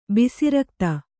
♪ bisi rakta